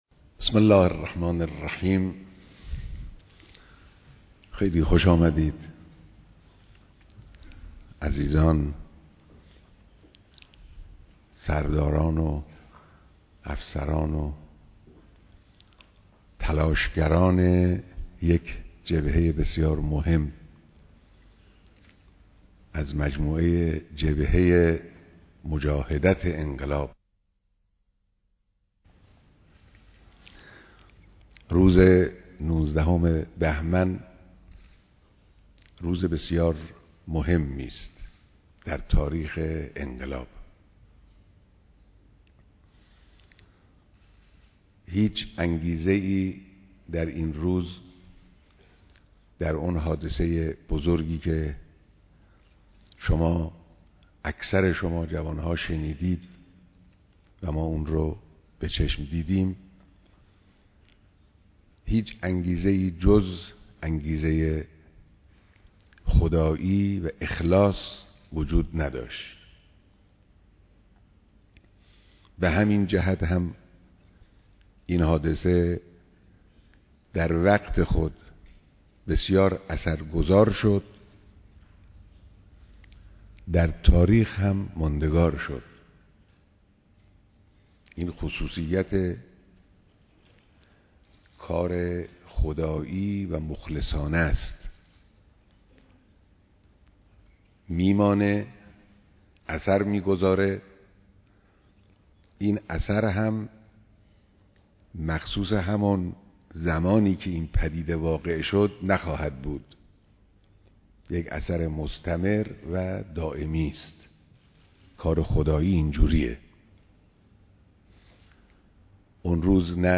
ديدار جمع زيادي از فرماندهان و كاركنان نيروي هوايي ارتش